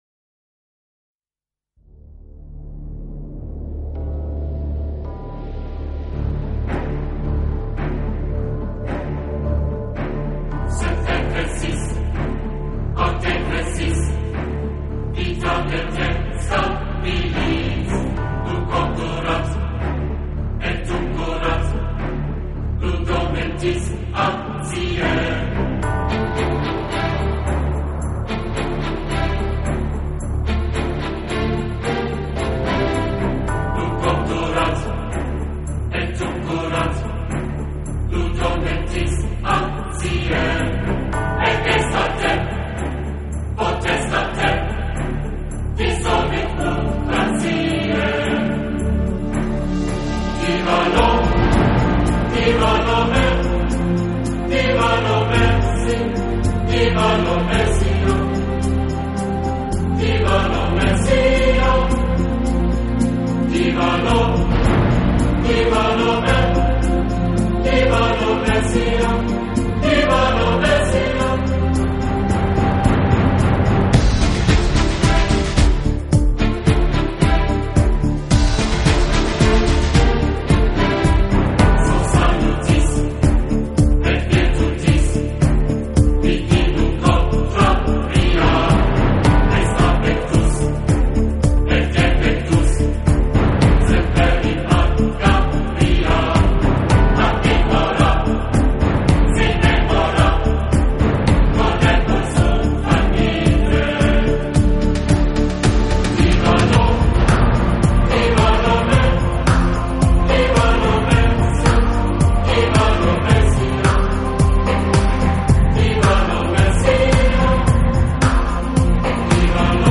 新世纪音乐
都是圣歌与现代器乐的融合，甚至乍听起来，还颇为相似。
是领唱上，都加重了女声的成分，因而整体风格都显得更柔和、更温暖。